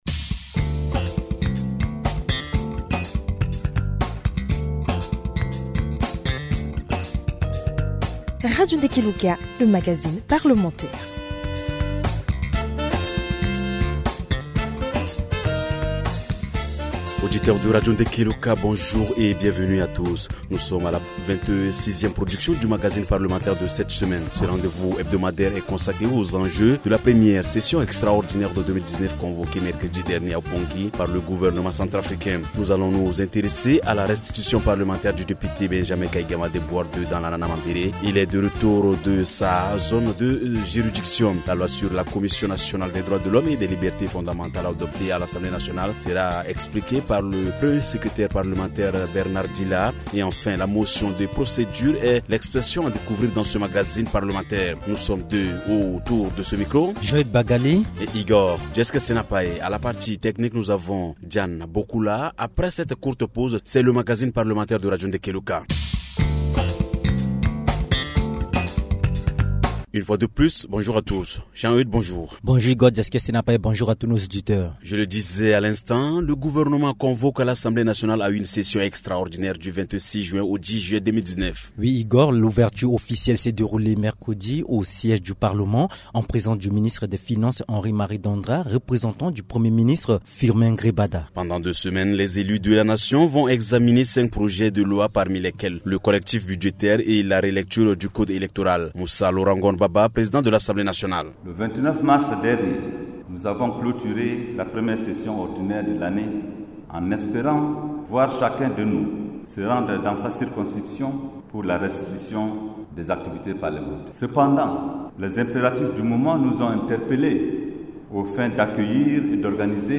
Cette fois le député Benjamin Kaïgama, pour faire le point sur les activités de restitution parlementaire qu’il a effectué dans sa circonscription. Le président de l’Assemblée nationale El Hadj Moussa Laurent Ngon Baba est l’invité spécial de cette émission.